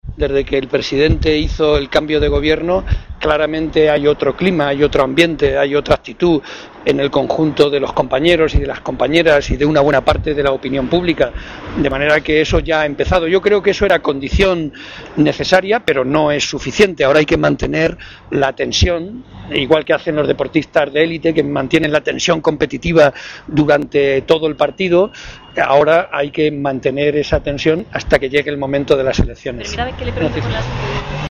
El secretario general de los socialistas castellano-manchegos y presidente del Consejo Territorial del PSOE, José María Barreda, ha señalado, a su llegada a la reunión de este órgano, que con el cambio de Gobierno ha habido una inflexión muy importante y perceptible por todos.